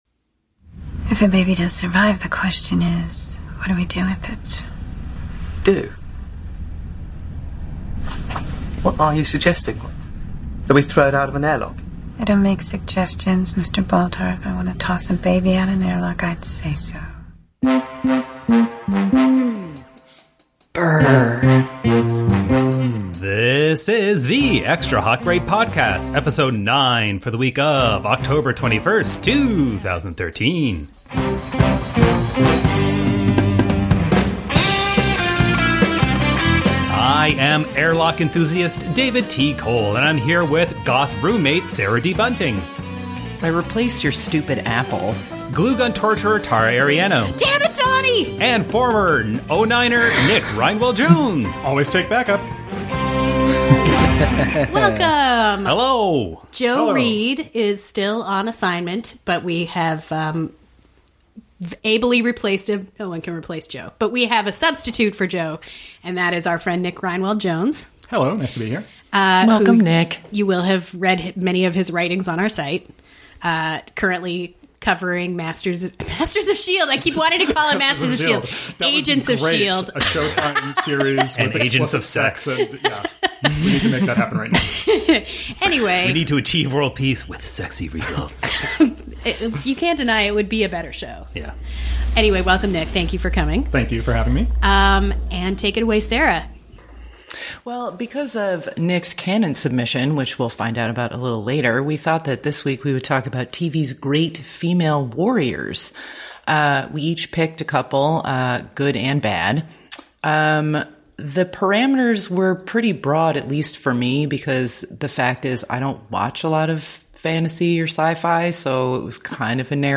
Then we consider a third-season Buffy episode for inclusion in The Canon, declare a winner and loser, and do a lot of singing in a non-regulation Game